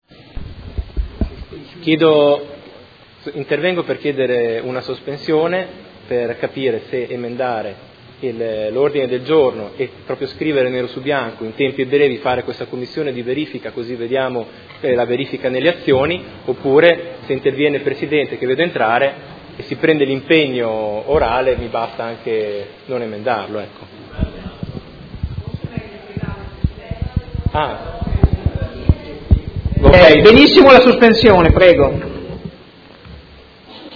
Seduta del 9/11/2017. Chiede sospensione su Ordine del Giorno presentato dai Consiglieri Arletti, Baracchi, Bortolamasi, Pacchioni, Forghieri, Di Padova, Venturelli, Poggi, Lenzini, Fasano e De Lillo (PD) avente per oggetto: Difesa dell’Accordo internazionale di COP21 sui cambiamenti climatici (Parigi 2015)